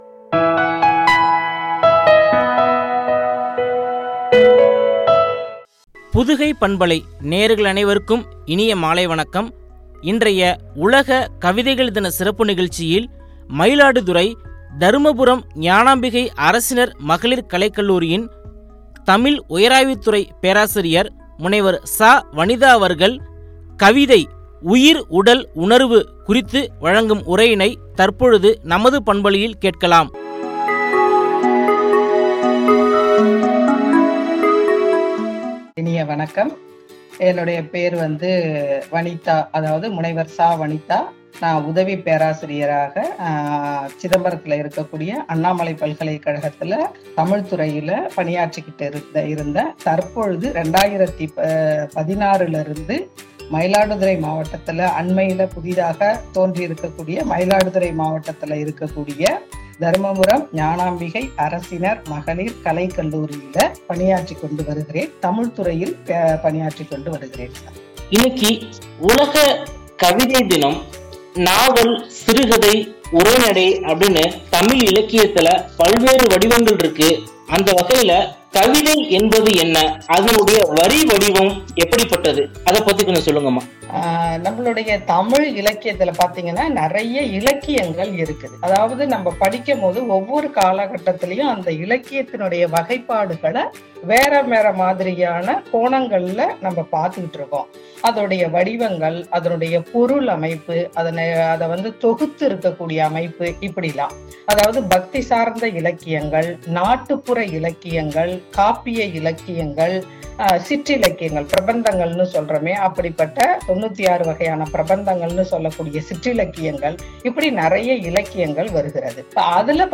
உணர்வு” குறித்து வழங்கிய உரையாடல்.